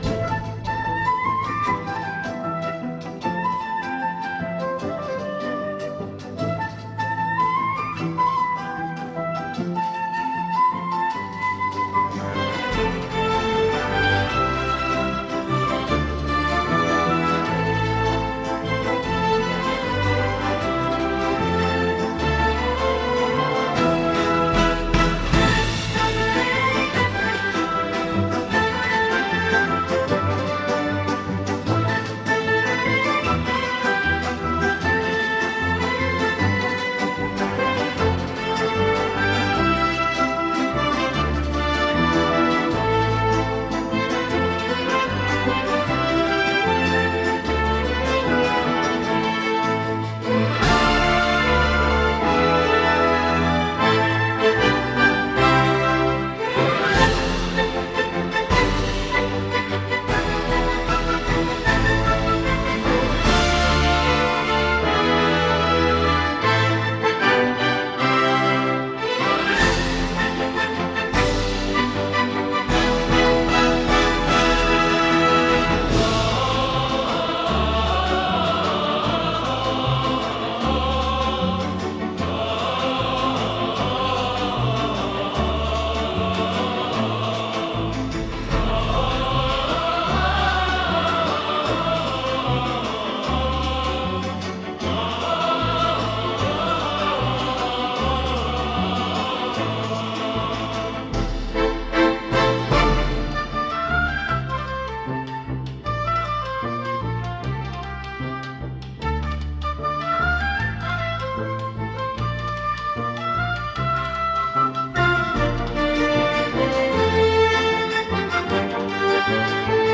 Und zum Thema Musik, da ist dieses mein bislang schönstes KI-Musikstück: